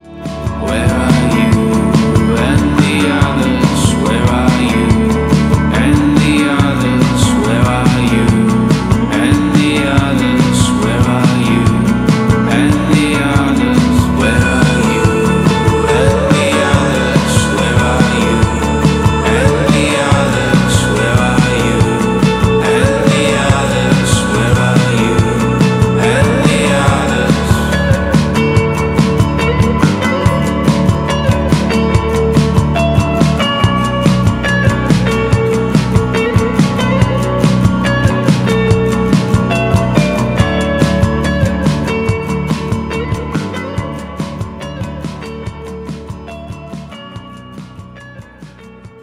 • Качество: 320, Stereo
гитара
атмосферные
красивый мужской голос
спокойные
романтичные
indie rock
new wave
post-punk